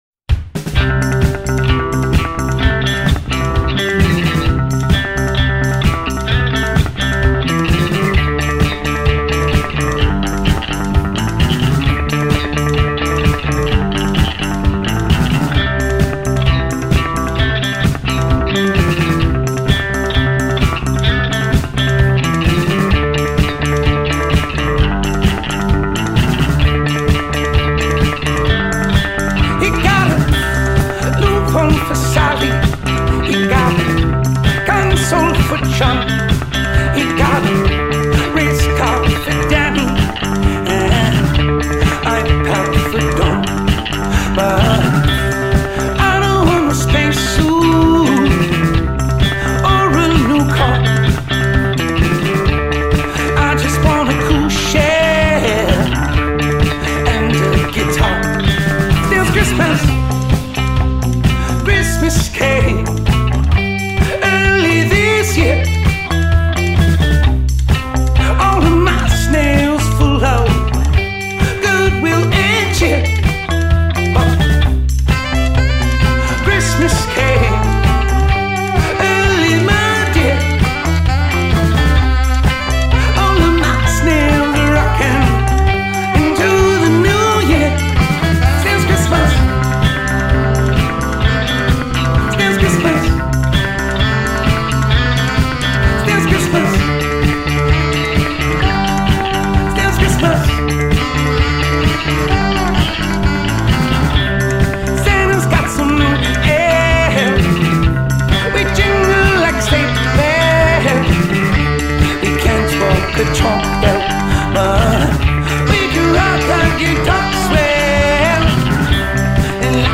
The dance-rock saxophone fueled Christmas tune